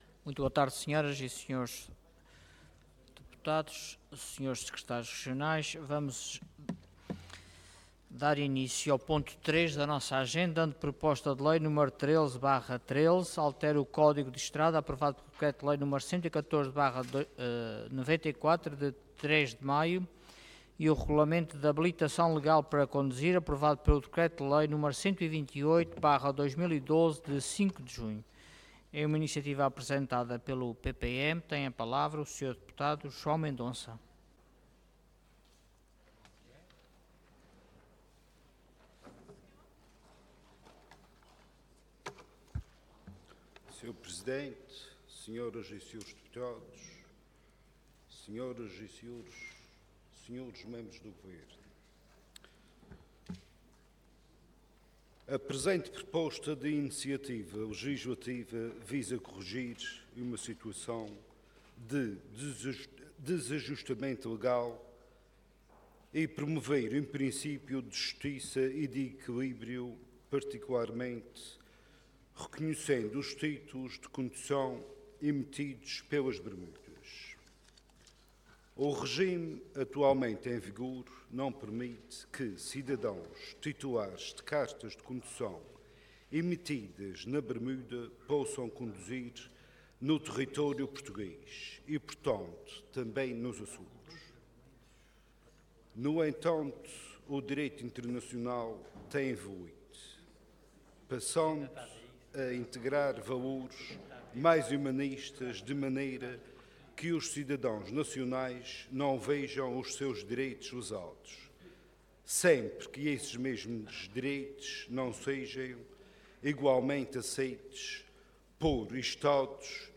Website da Assembleia Legislativa da Região Autónoma dos Açores
Intervenção
Orador João Mendonça Cargo Deputado